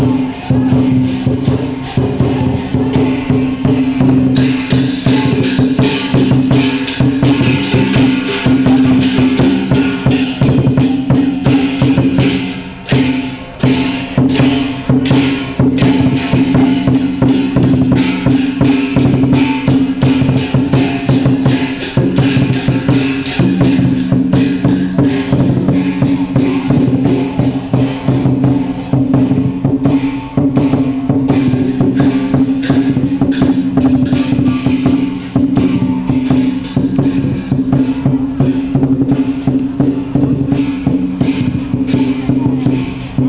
Musikgruppen spielten zur Feier des Tages auf.
Rechts eine kleine Gruppe mit Schlaginstrumenten.